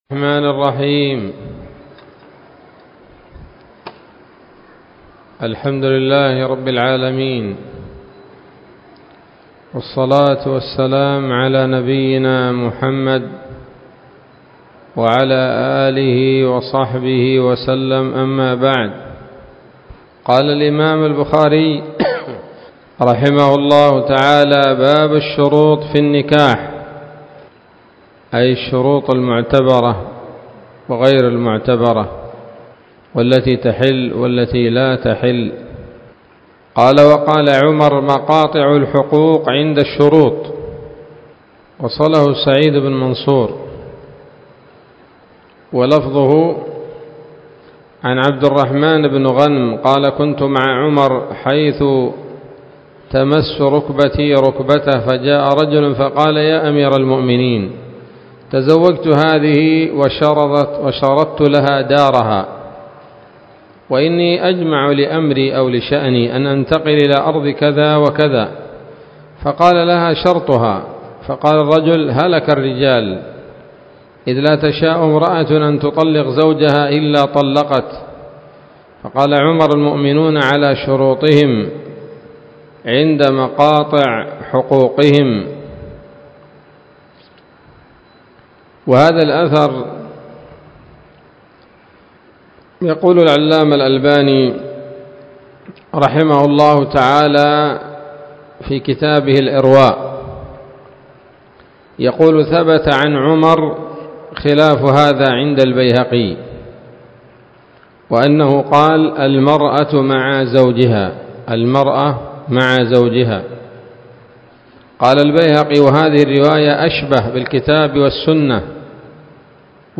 الدرس السابع والأربعون من كتاب النكاح من صحيح الإمام البخاري